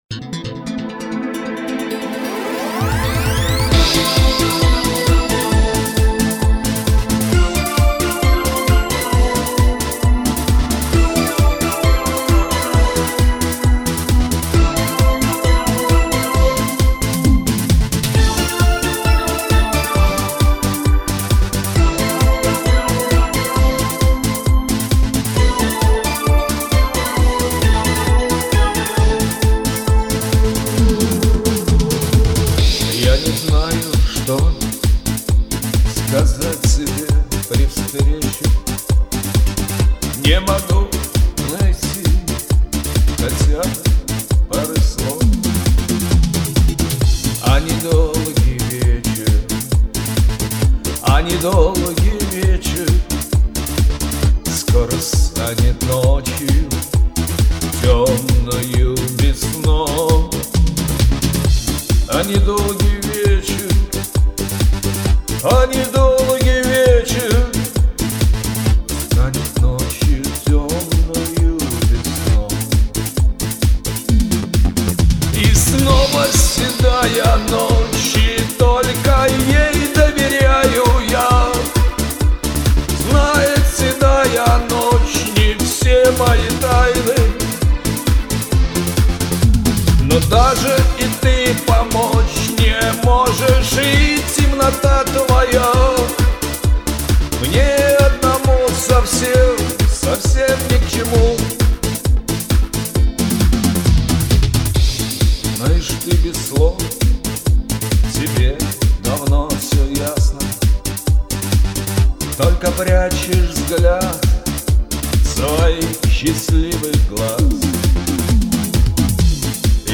Тональность тяжеловата для исполнителей но ребята молодцы.